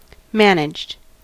Ääntäminen
Ääntäminen US : IPA : [ˈmæn.ɪdʒd] Haettu sana löytyi näillä lähdekielillä: englanti Managed on sanan manage partisiipin perfekti.